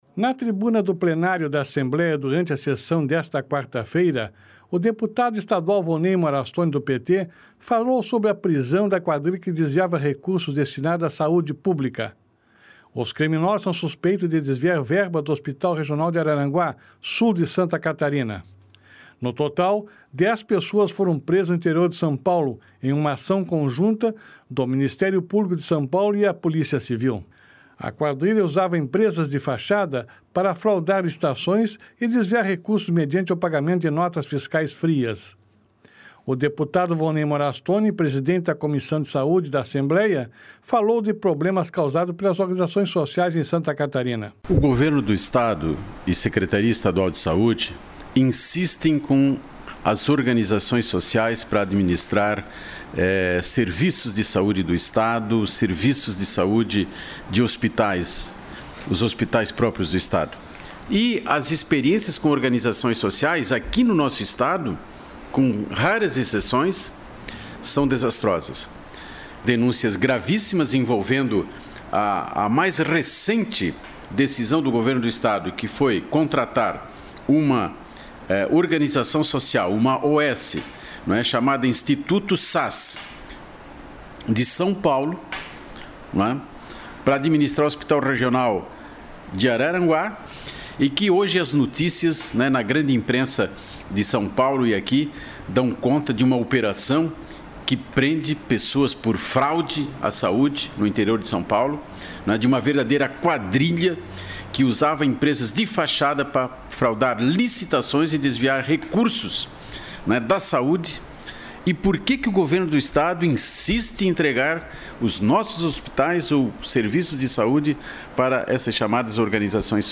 Deputado Volnei Morastoni fala sobre prisão de quadrilha que desviava recursos da saúde